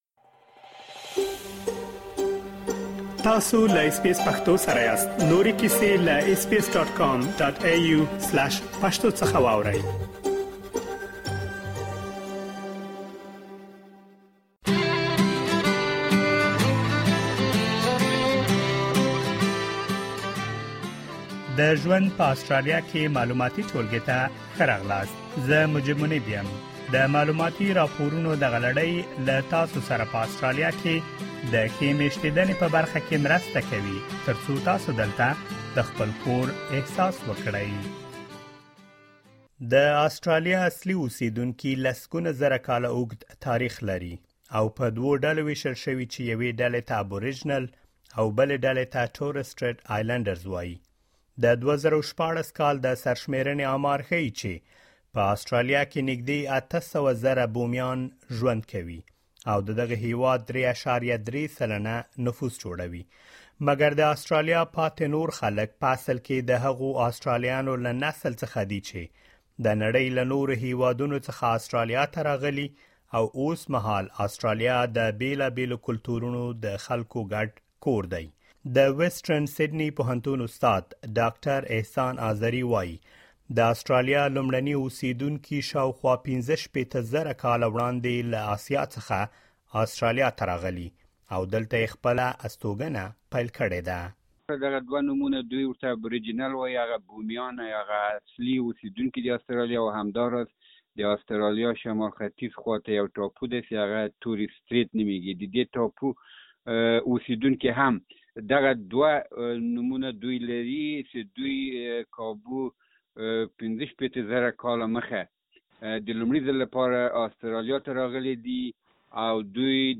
After the European settlement in Australia, the Afghan cameleers were brought to Australia between the 1860s and 1930 to help the continent's settlers trek the outback. In this informative report, we have gathered information about the history of first people and the Afghan Cameleers.